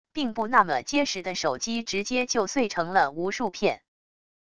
并不那么结实的手机直接就碎成了无数片wav音频